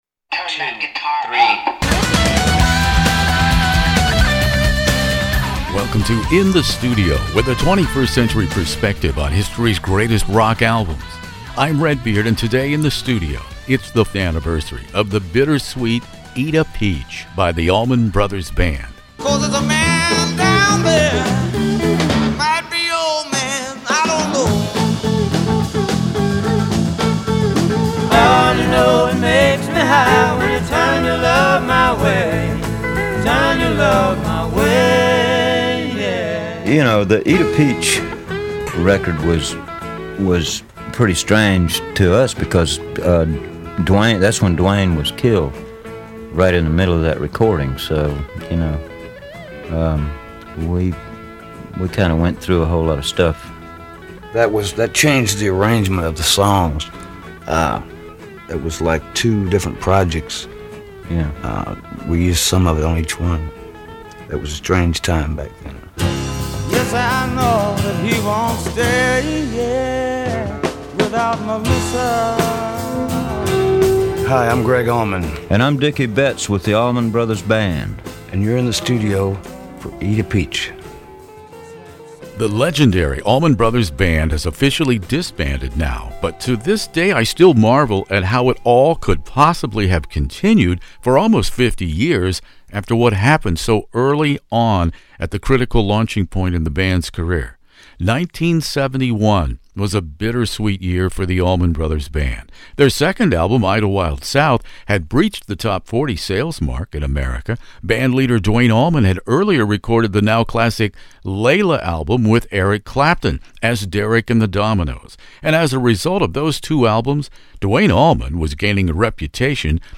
One of the world's largest classic rock interview archives, from ACDC to ZZ Top, by award-winning radio personality Redbeard.
In this interview marking the fiftieth anniversary of this bittersweet album, Gregg Allman (who died May 2017) and ex-guitarist Dickey Betts (died 4/18/24) reveal how the music sustained the brotherhood.